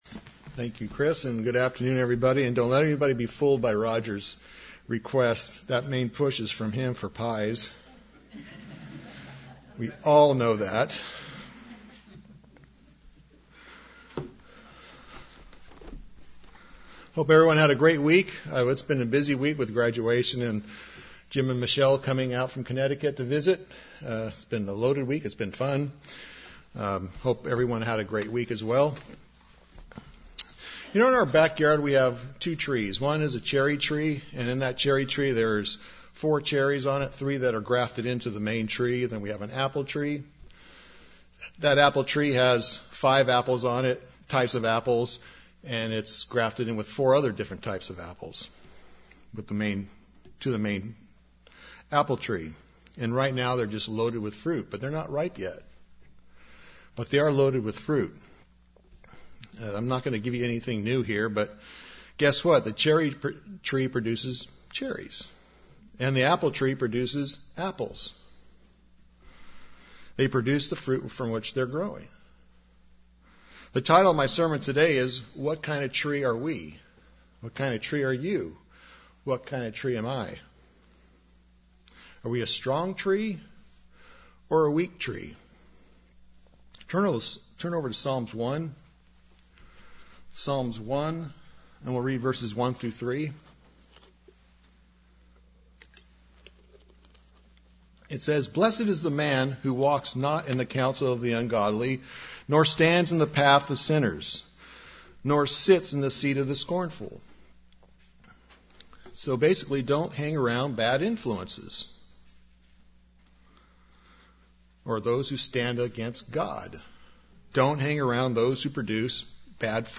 Sermons
Given in Burlington, WA